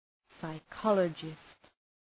Προφορά
{saı’kɒlədʒıst}